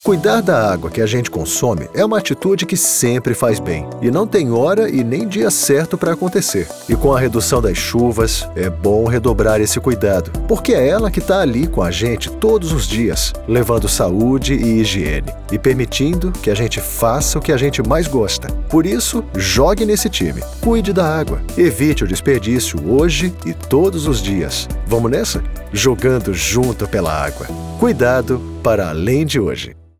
E-learning
Mi tono es conversacional, pero también puedo hacer la voz de un adulto joven y de una persona mayor.